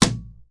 飞机起飞
描述：飞机起飞时的声音
Tag: 飞机 音效 振耳 音效 振耳